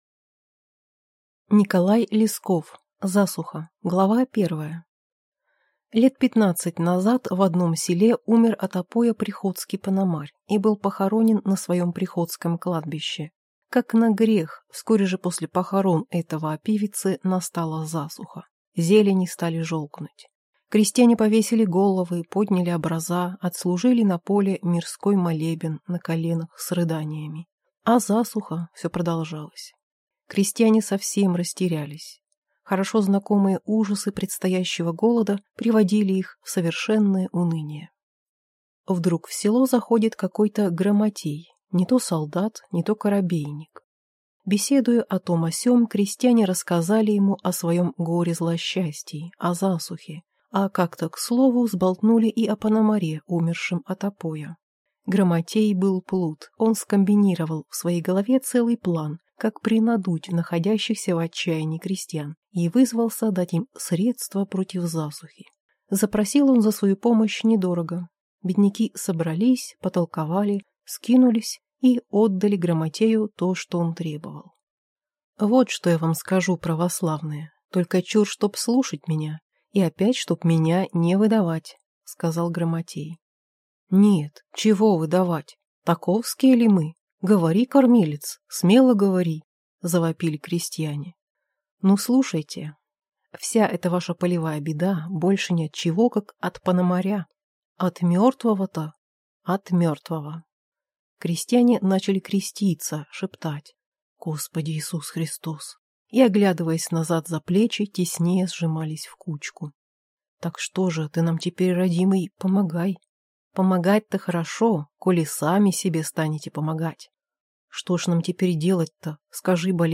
Аудиокнига Засуха | Библиотека аудиокниг